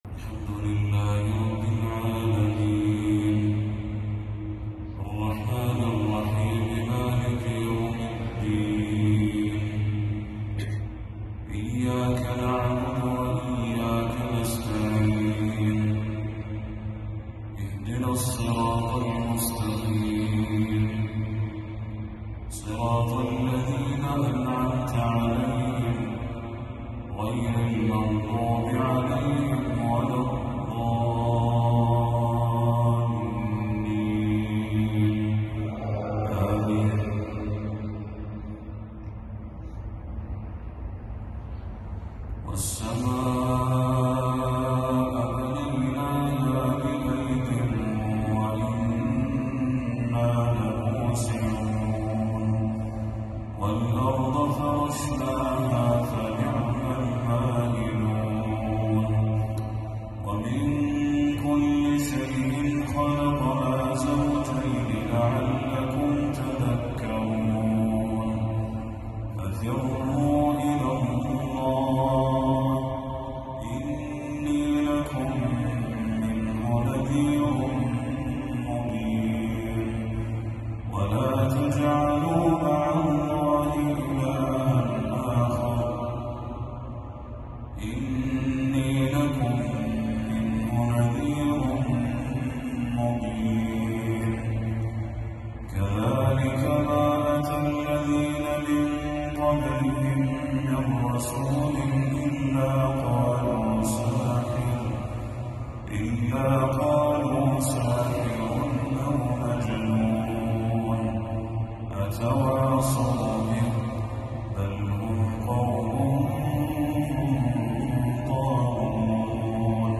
تلاوة من سورة الذاريات للشيخ بدر التركي | مغرب 10 صفر 1446هـ > 1446هـ > تلاوات الشيخ بدر التركي > المزيد - تلاوات الحرمين